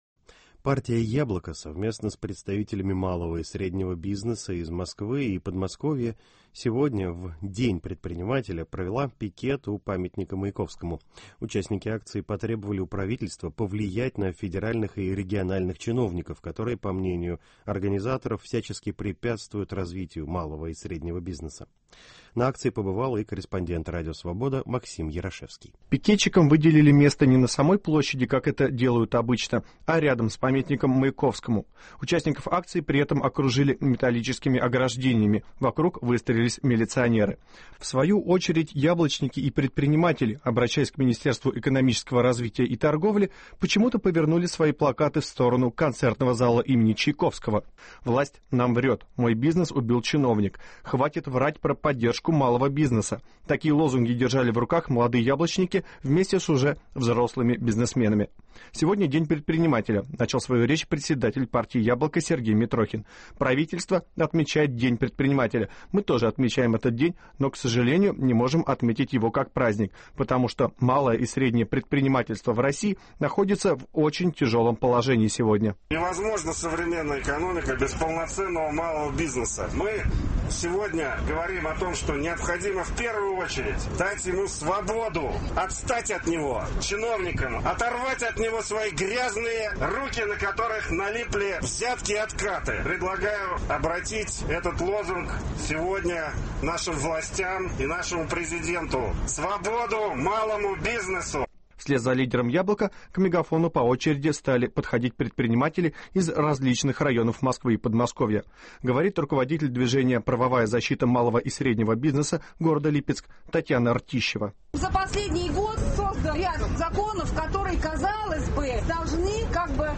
Митинг в защиту предпринимателей прошел в Москве